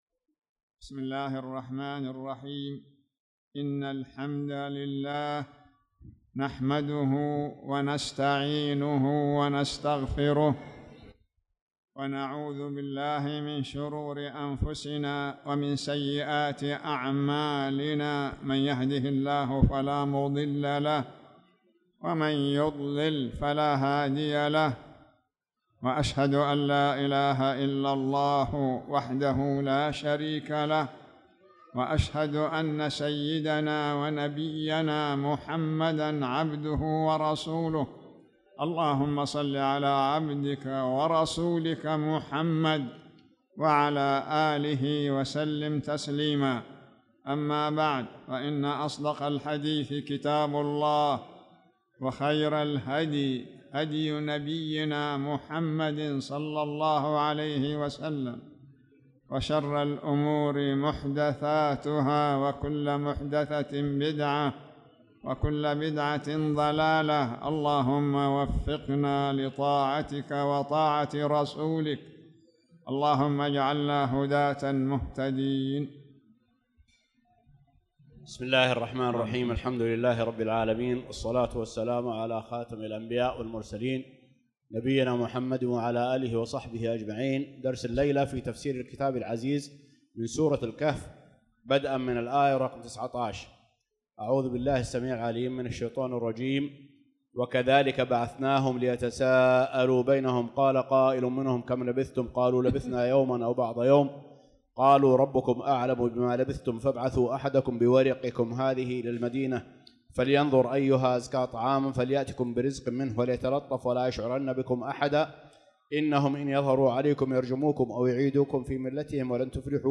تاريخ النشر ٢٩ شوال ١٤٣٧ هـ المكان: المسجد الحرام الشيخ